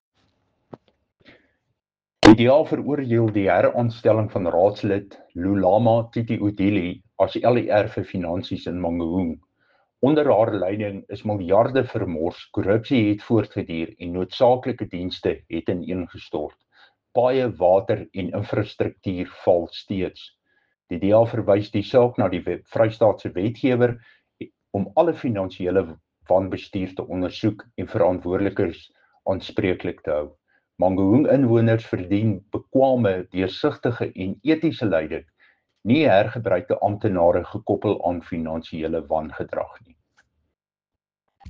Afrikaans soundbites by Cllr Dirk Kotze and